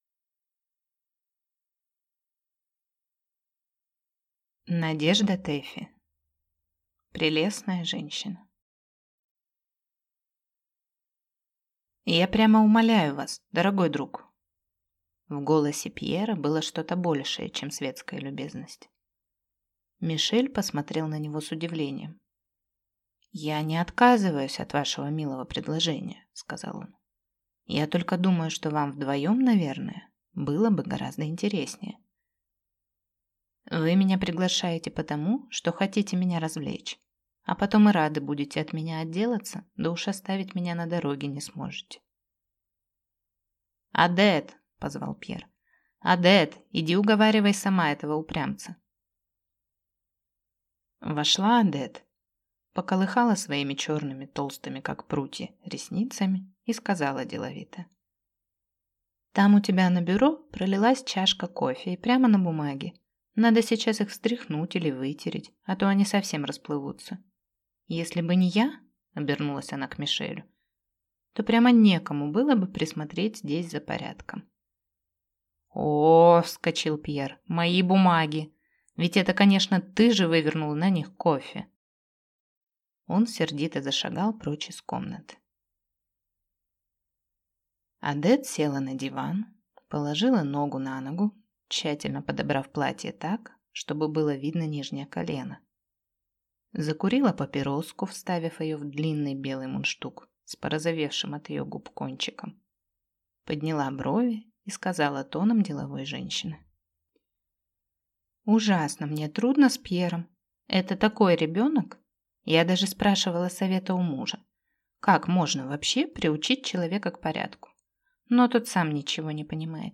Аудиокнига Прелестная женщина | Библиотека аудиокниг